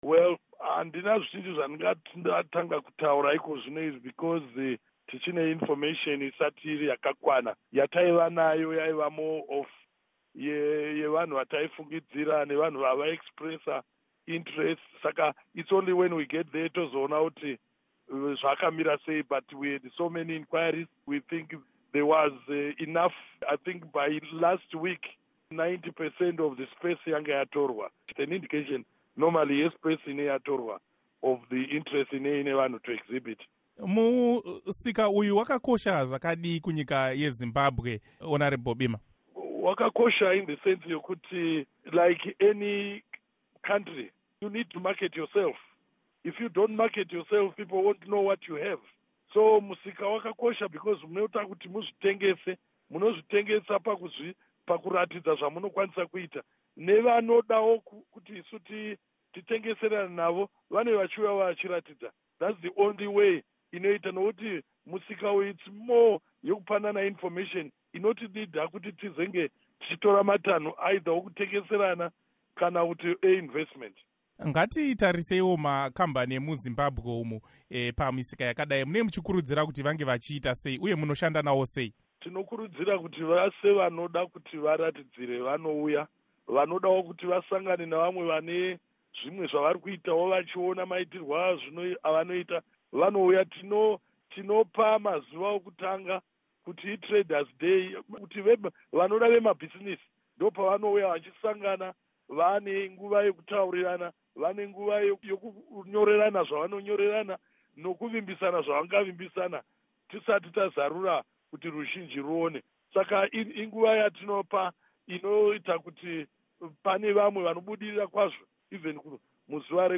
Hurukuro naVaMike Bimha